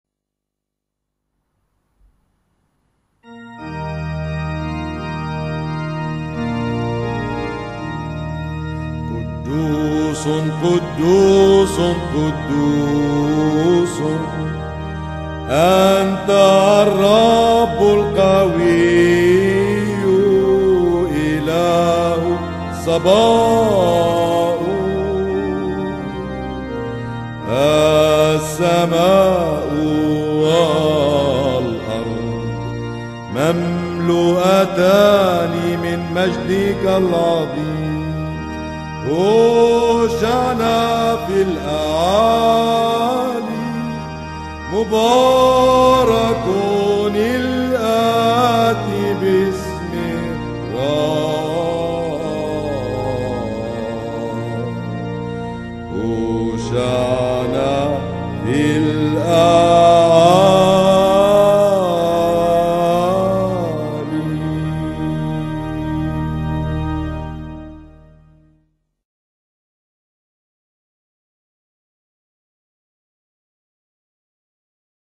127 قدوس (4) لحن خاص لقداس الموتى